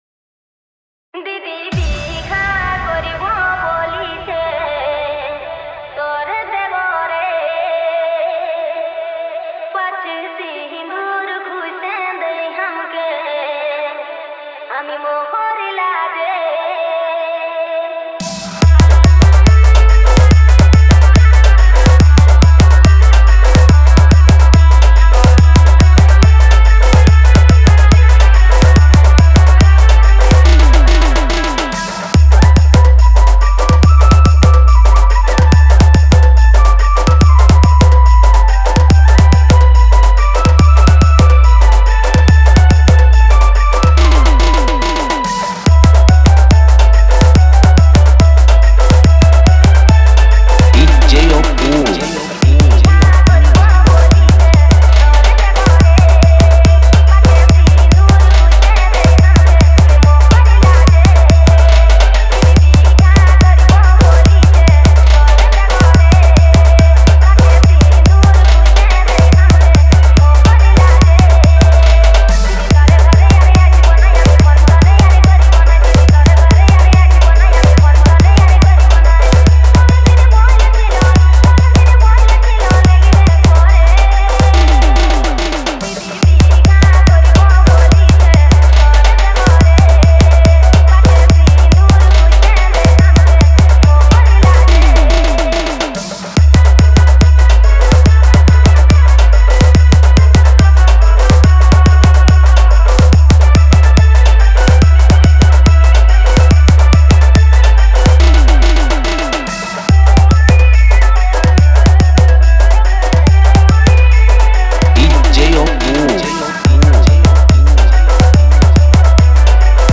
Purulia Roadshow Matal Dance Mix